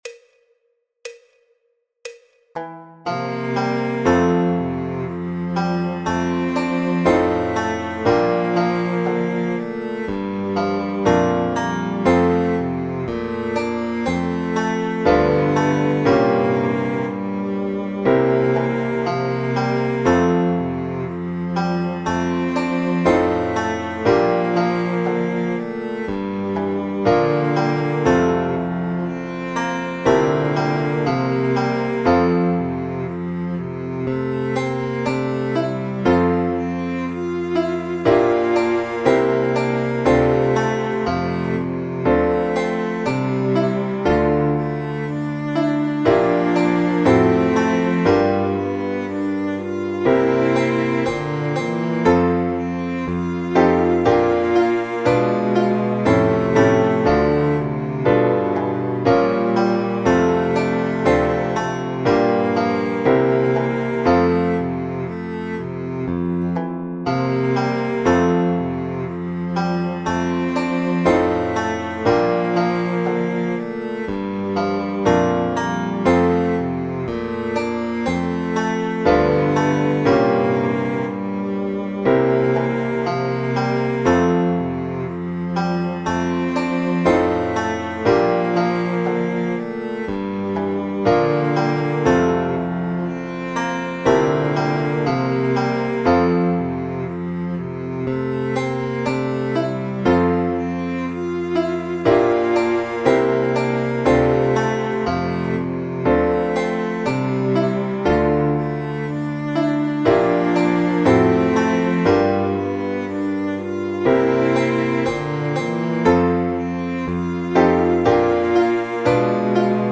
El MIDI tiene la base instrumental de acompañamiento.